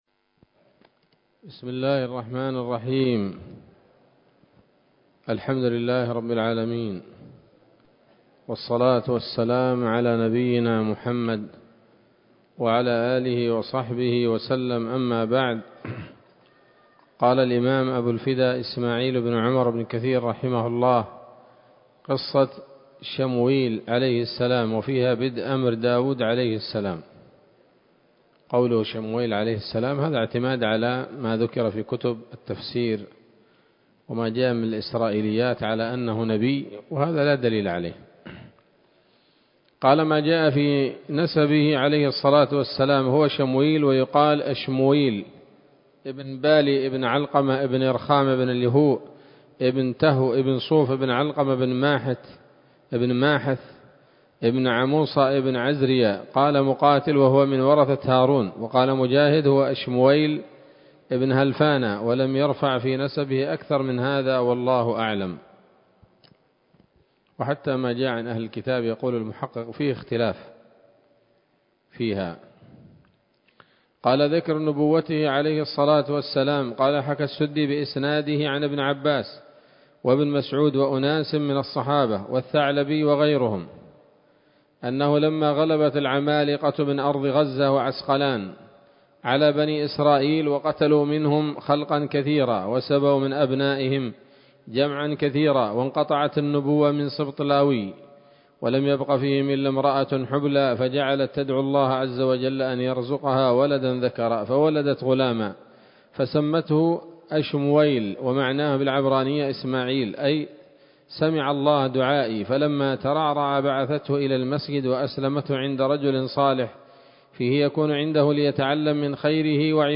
‌‌الدرس السادس عشر بعد المائة من قصص الأنبياء لابن كثير رحمه الله تعالى